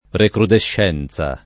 [ rekrudešš $ n Z a ]